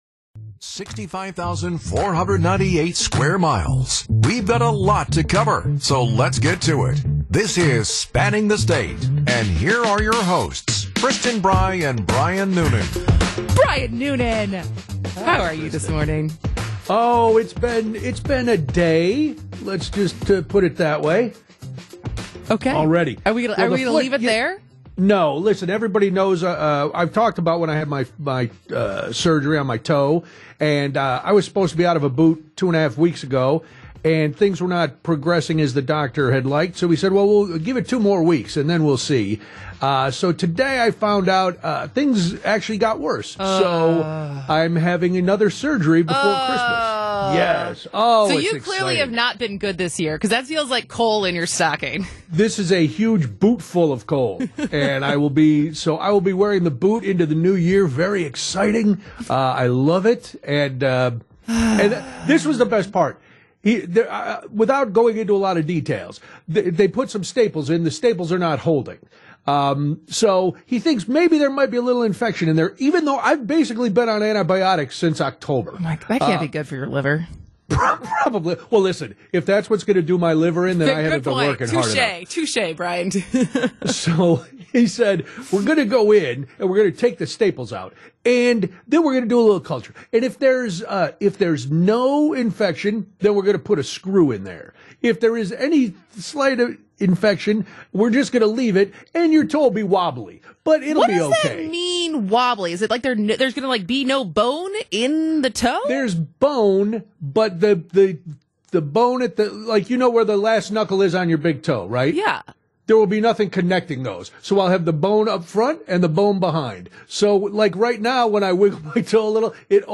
Fans will hear the stories being told by those who live and cover those communities firsthand for the most accurate and relevant information.